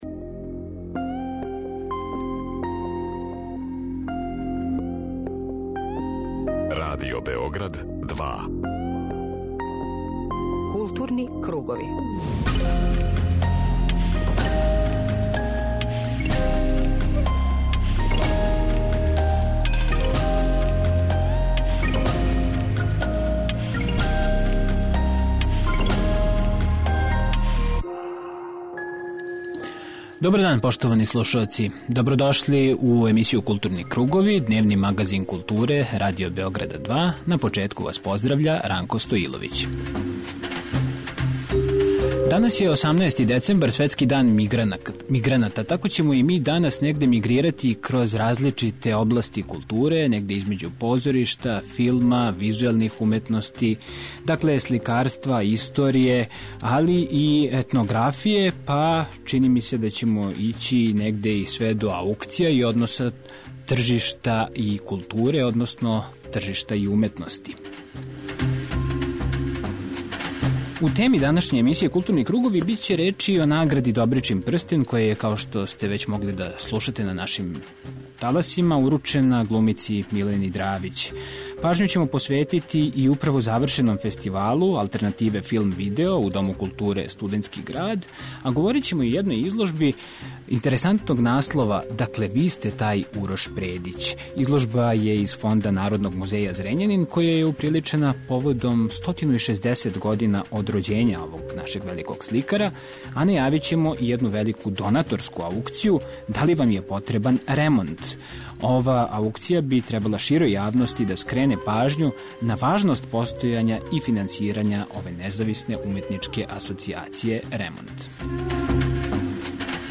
Mагазин културе Радио Београда 2